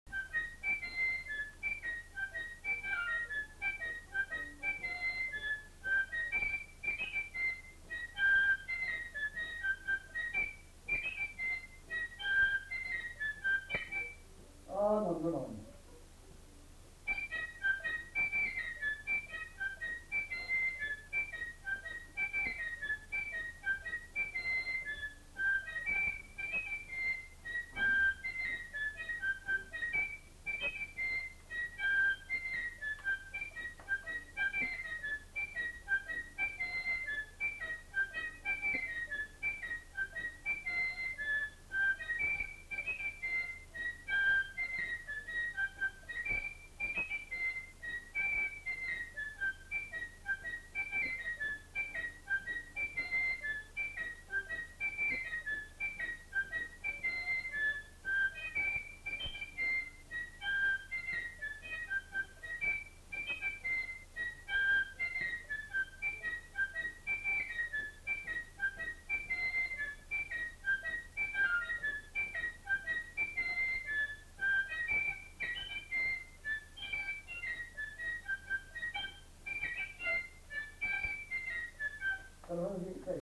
Répertoire d'airs à danser joué à la flûte à trois trous et à l'harmonica
Rondeau